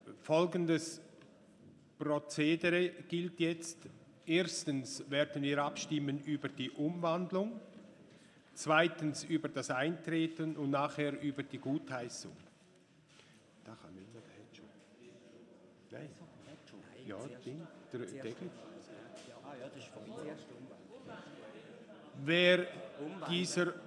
13.6.2019Wortmeldung
Ratspräsident:
Session des Kantonsrates vom 11. bis 13. Juni 2019